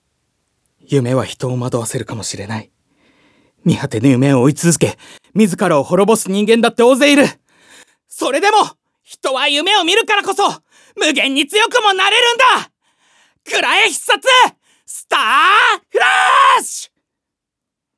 セリフ1